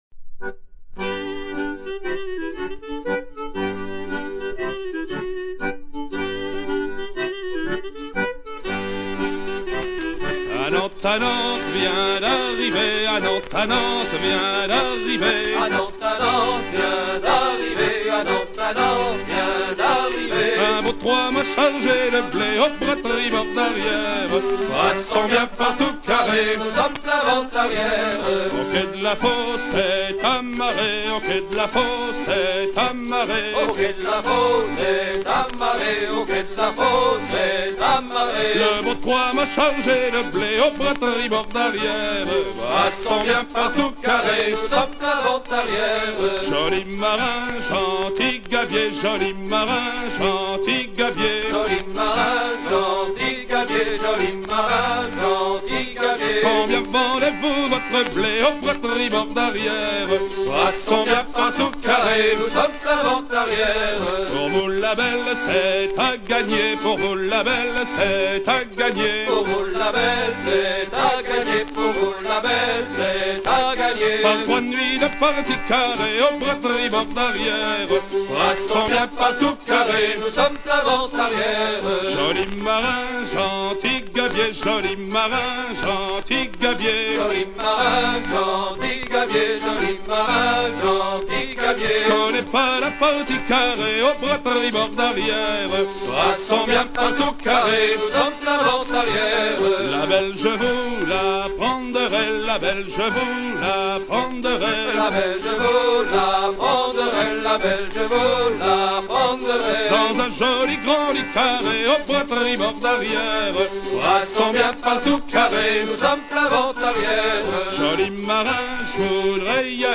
gestuel : à virer au cabestan
Pièce musicale éditée